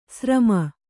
♪ srama